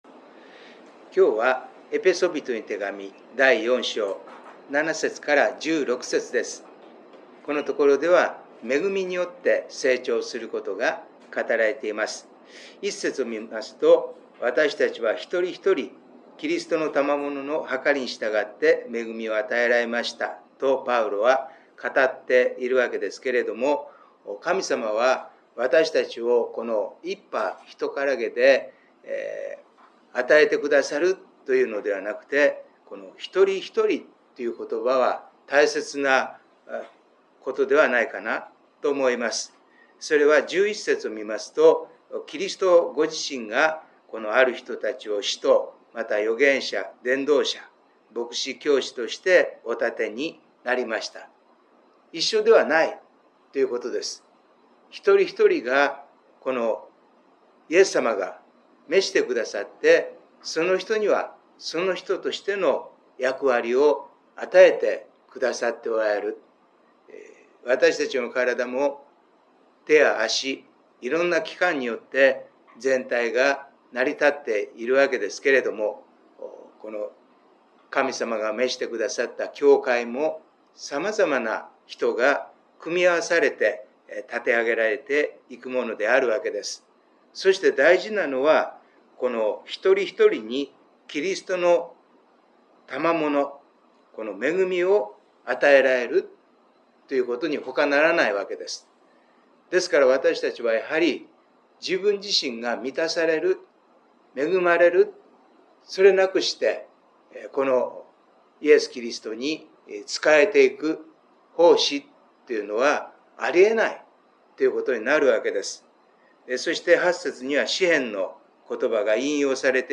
礼拝メッセージ│日本イエス・キリスト教団 柏 原 教 会